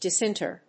音節dis・in・ter 発音記号・読み方
/dìsɪntˈɚː(米国英語), dìsɪntˈəː(英国英語)/